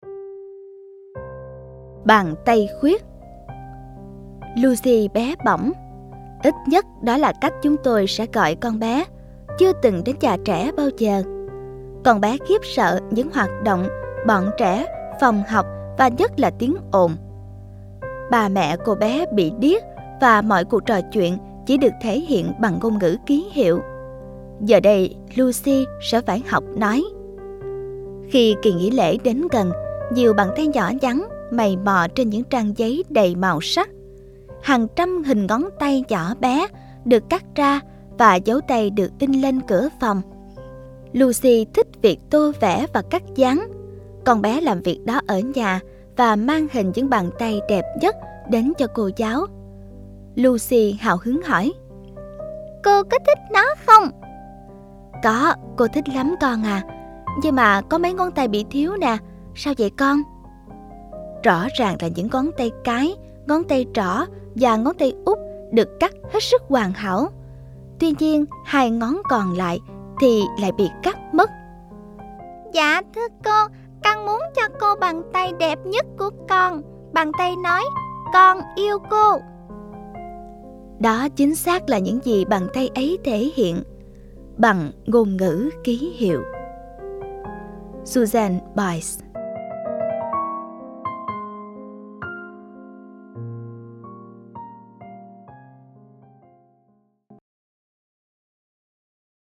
Sách nói Chicken Soup 20 - Hạt Giống Yêu Thương - Jack Canfield - Sách Nói Online Hay